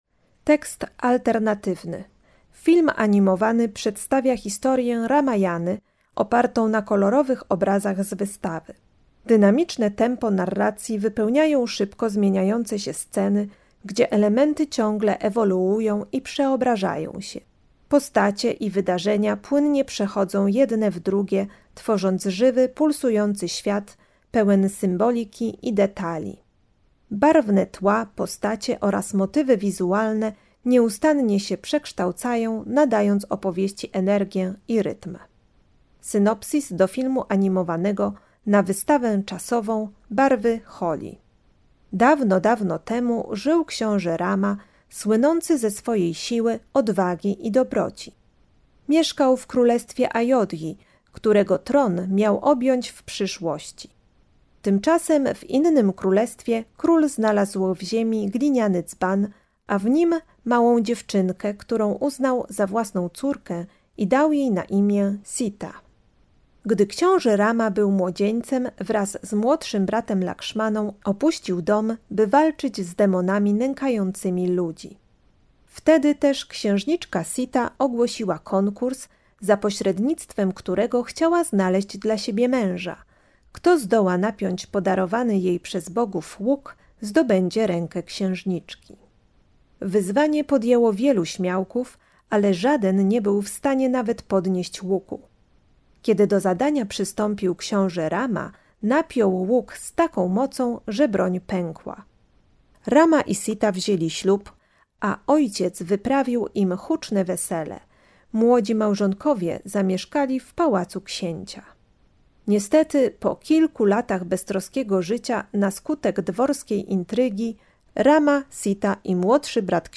200_ramajana_film-animowany_tekst-alternatywny-i-synopsis.mp3